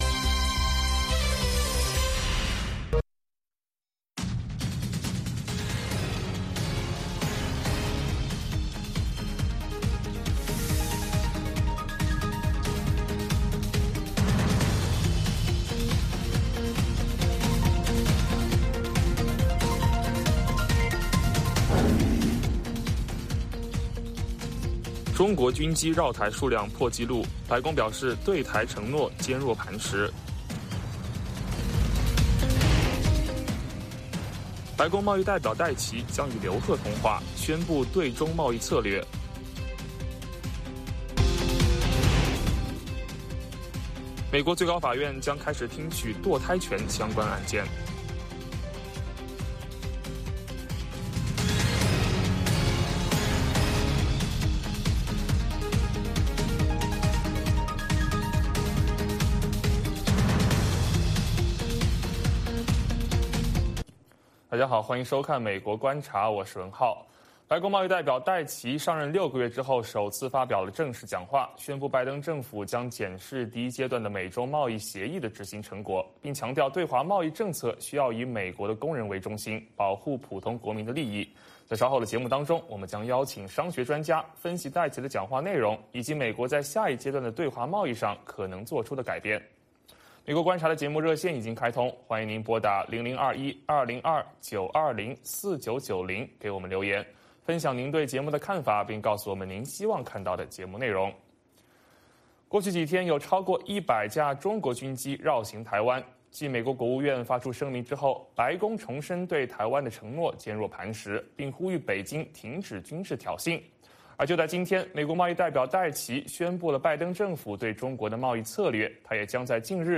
北京时间早上6点广播节目，电视、广播同步播出VOA卫视美国观察。 “VOA卫视 美国观察”掌握美国最重要的消息，深入解读美国选举，政治，经济，外交，人文，美中关系等全方位话题。节目邀请重量级嘉宾参与讨论。